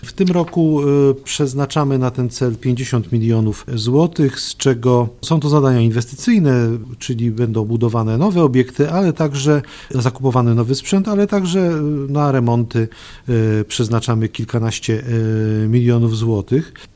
Mówi wicemarszałek województwa mazowieckiego Wiesław Raboszuk: